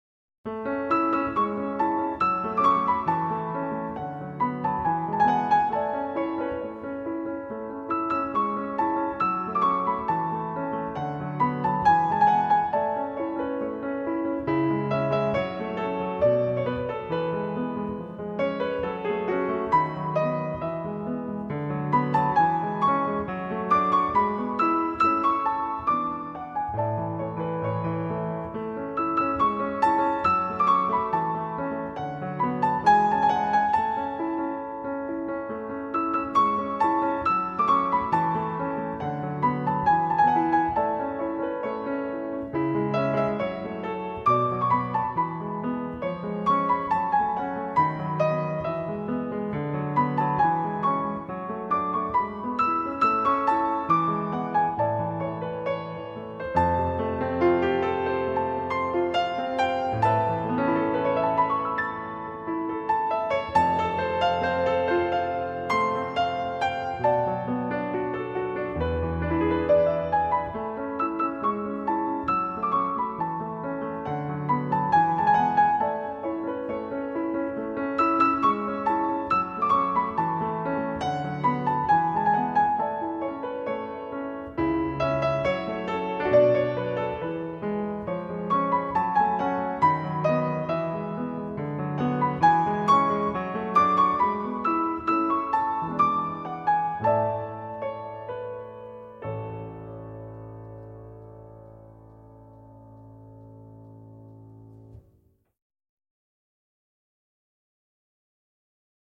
轻音乐
背景音乐为舒缓美妙柔和的声音背景配乐
该BGM音质清晰、流畅，源文件无声音水印干扰